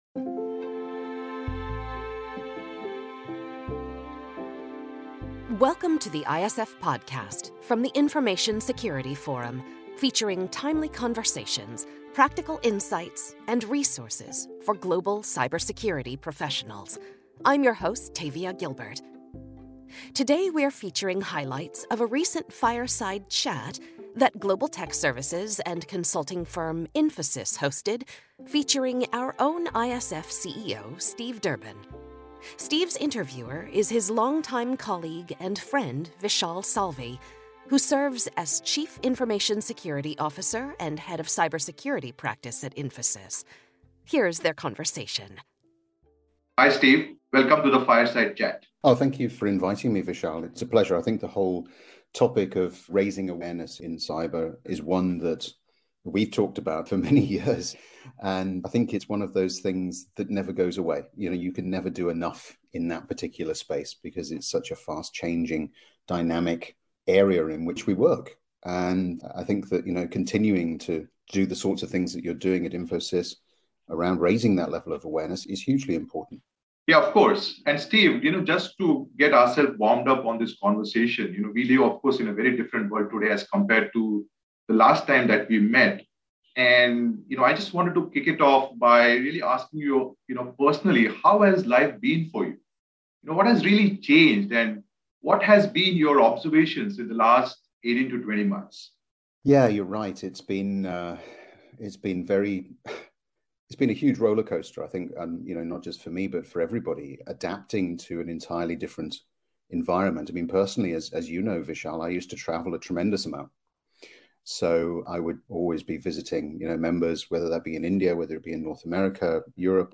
Today, we’re featuring highlights of a recent “fireside chat” hosted by global tech services and consulting firm Infosys